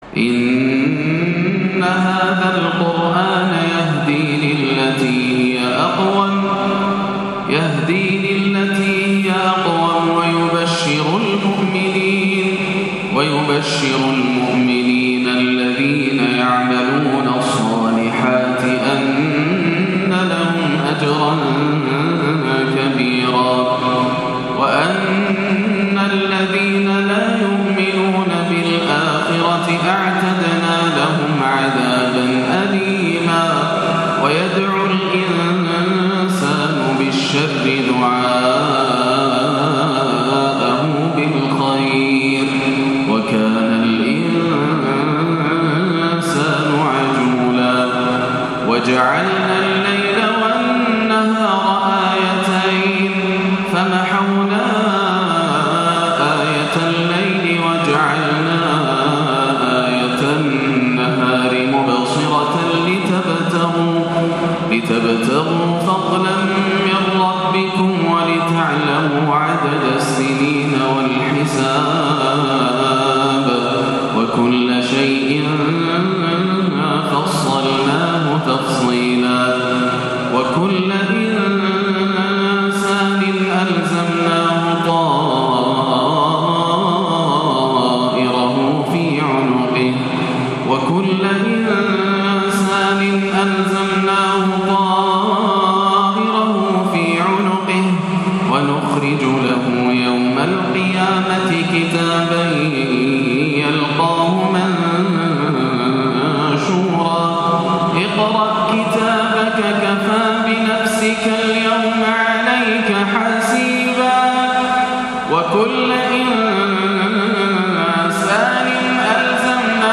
(وكل إنسان ألزمناه طائره في عنقه) من أروع العشائيات تفوق الوصف - عشاء الأربعاء 18-7 > عام 1439 > الفروض - تلاوات ياسر الدوسري